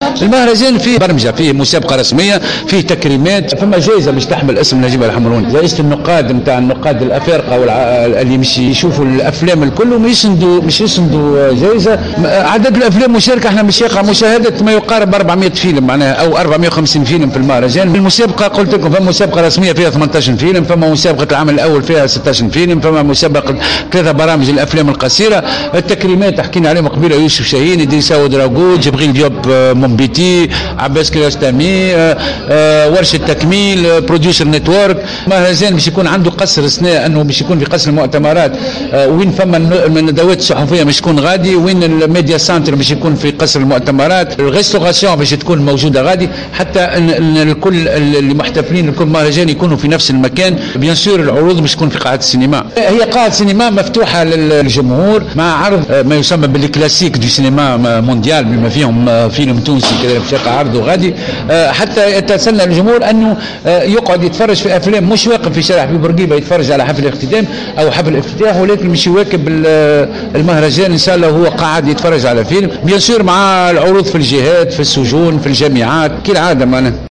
في ندوة صحفية عقدت اليوم بمقر النقابة الوطنية للصحفيين التونسيين